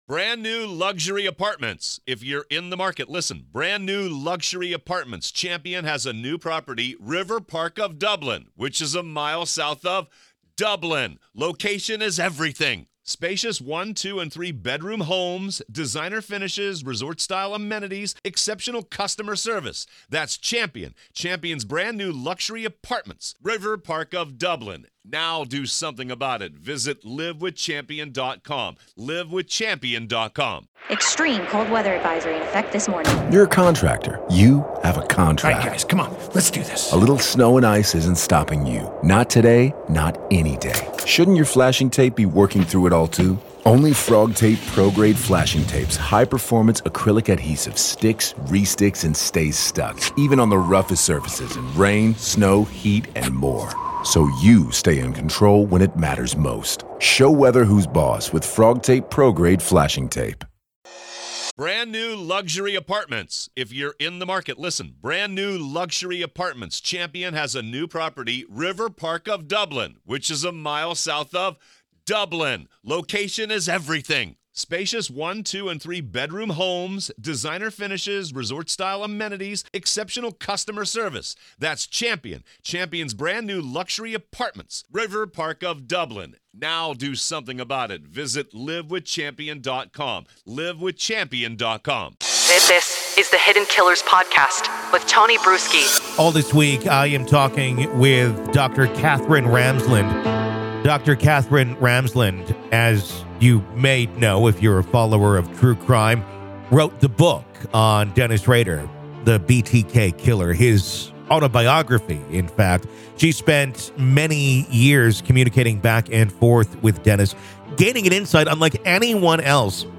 Dr. Katherine Ramsland Interview Behind The Mind Of BTK Part 3